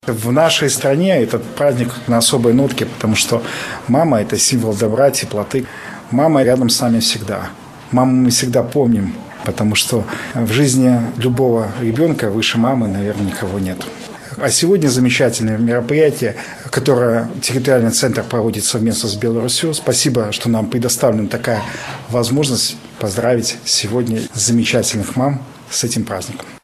В Барановичах в Территориальном центре прошло торжественное мероприятие